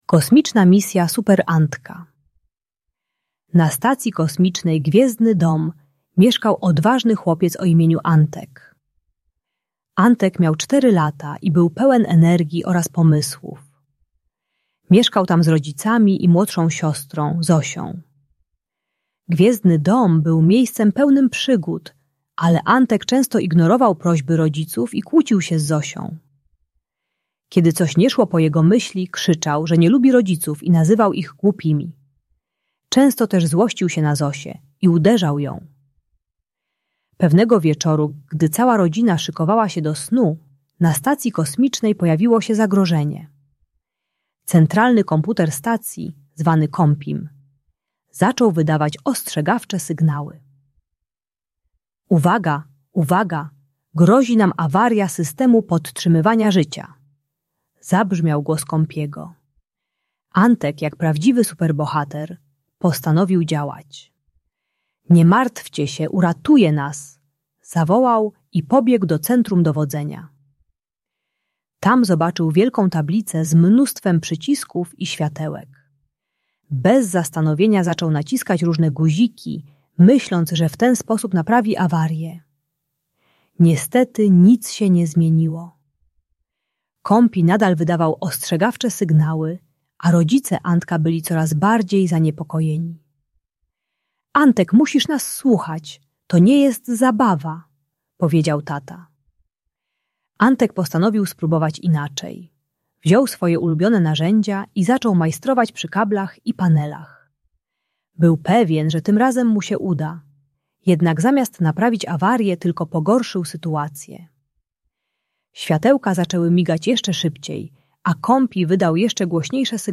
Kosmiczna Misja Super Antka - Bunt i wybuchy złości | Audiobajka